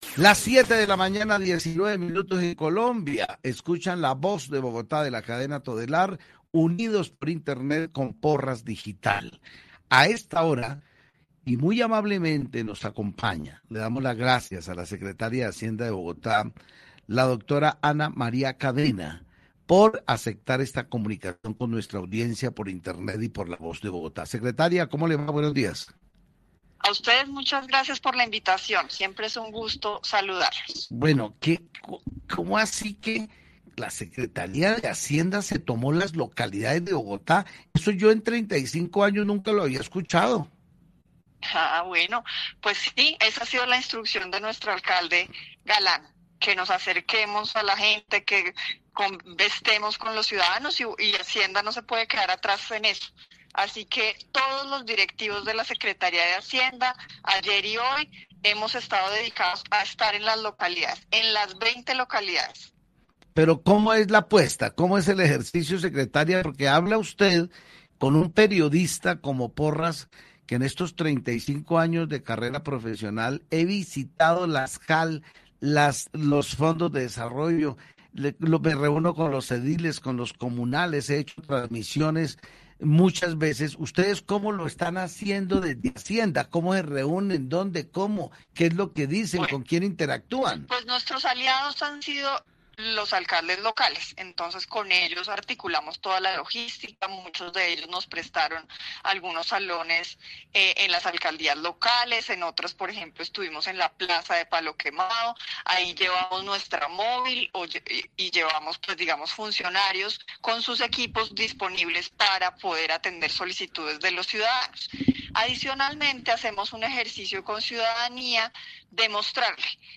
Entrevista Secretaria de Hacienda de Bogotá